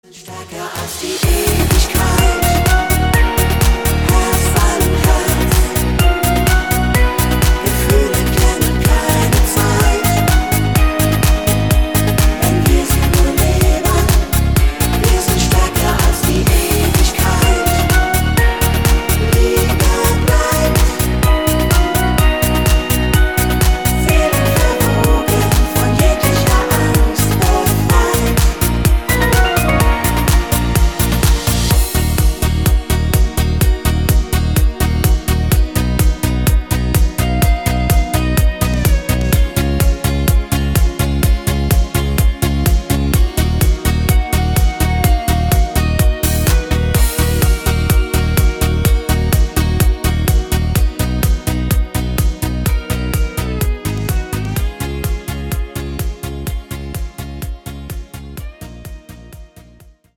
neu und absoluter fetzig